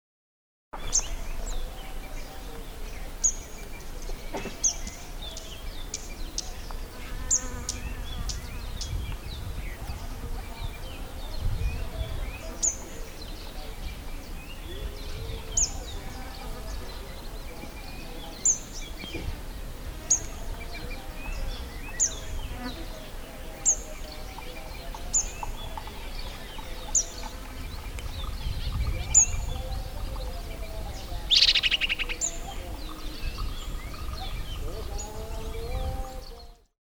Thailand: Scarlet-backed Flowerpecker (Dicaeum cruentatum)